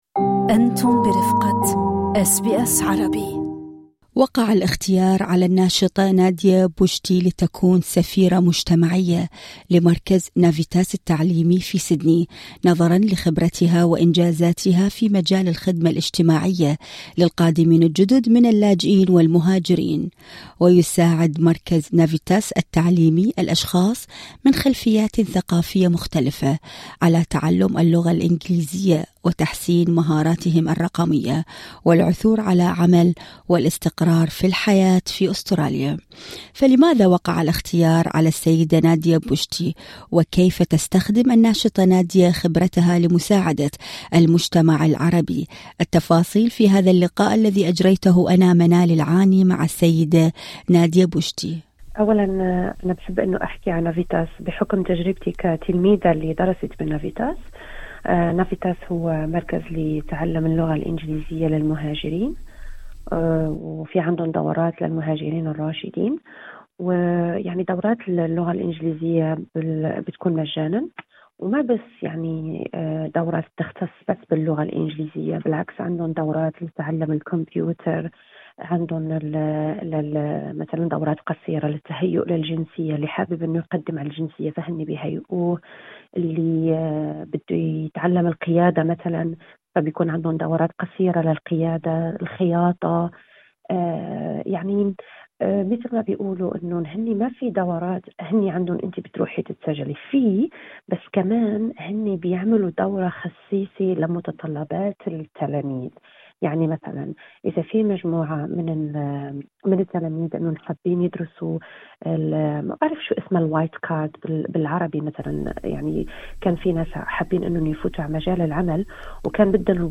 التفاصيل في هذا اللقاء الصوتي اعلاه